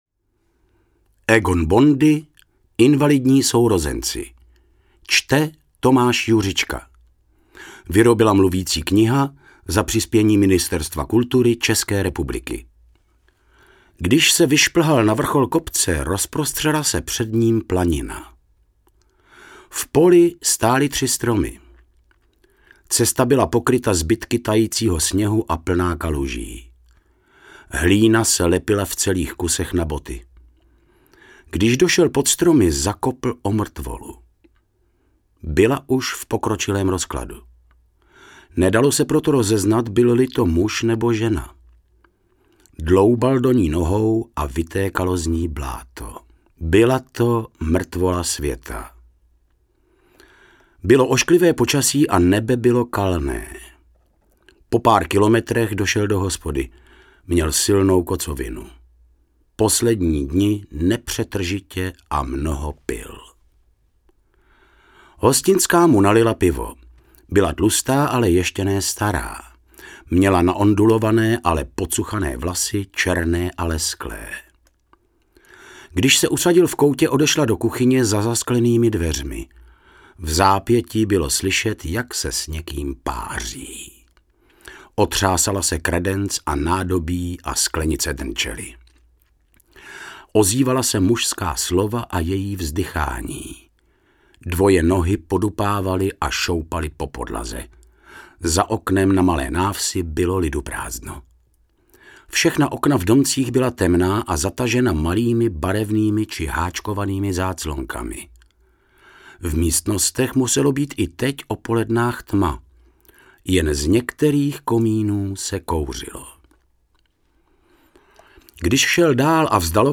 Mluvící kniha z.s.